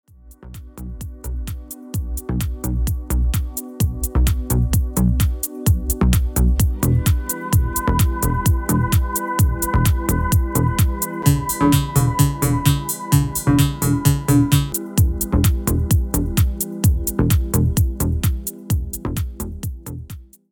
• Качество: 320, Stereo
Electronic
спокойные
без слов
цикличные
Спокойная мелодия без слов